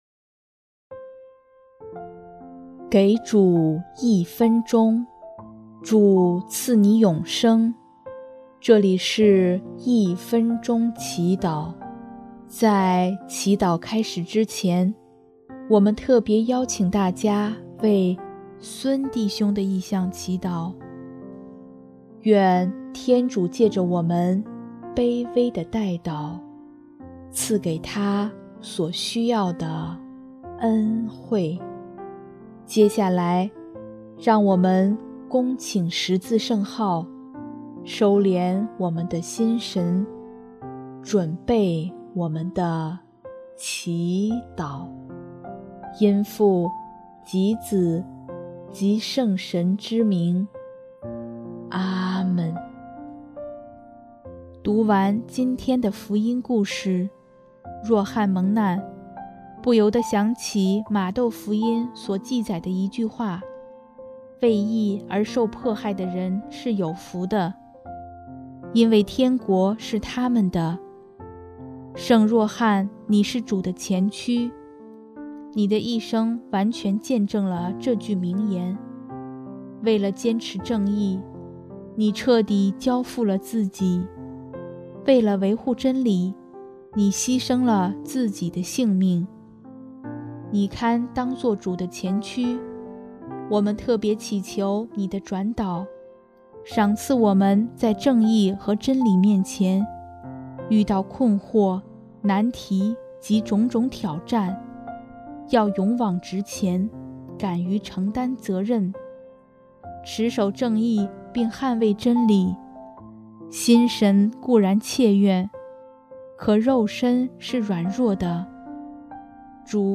【一分钟祈祷】|8月29日 若翰蒙难
音乐： 主日赞歌《预备主道路》